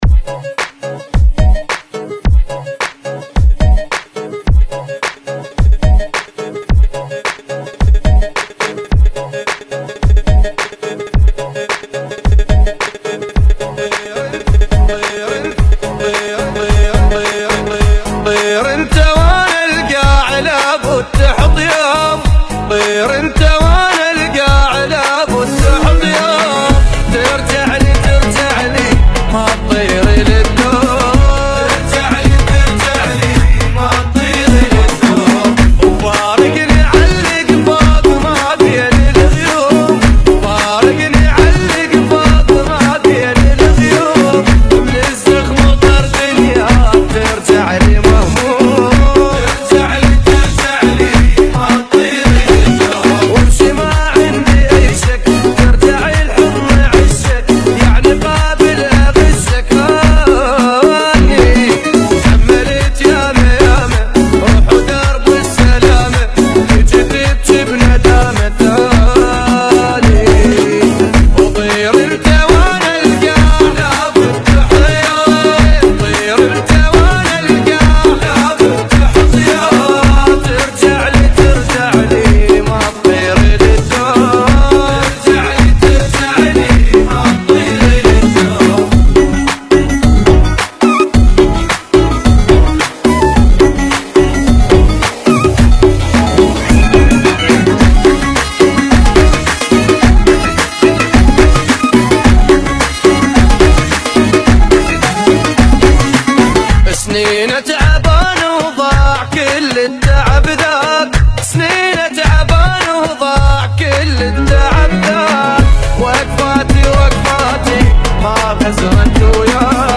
Funky [ 108 Bpm